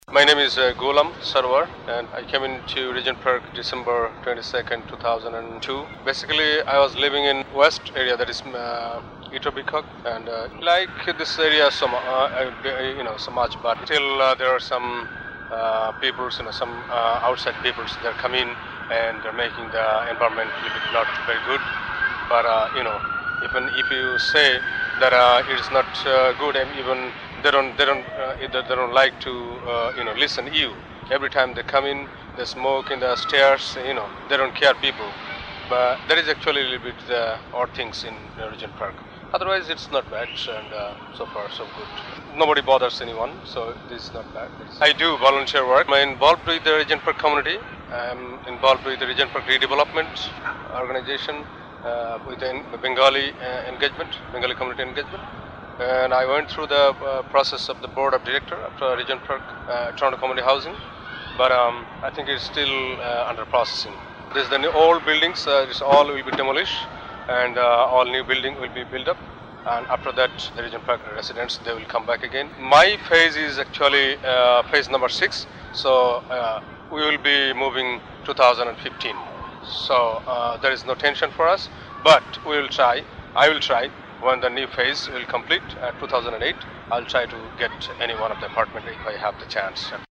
Journey Home is a story-telling project that explores the journey from homeland to Canada, through the voices of Regent Park residents.